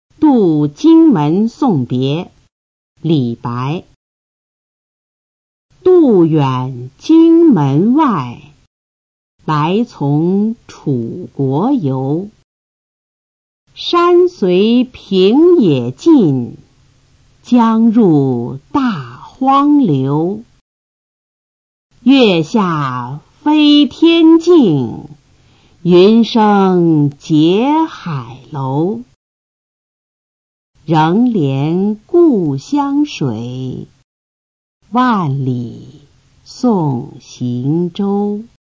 《渡荆门送别》原文和译文（含赏析、mp3朗读）　/ 李白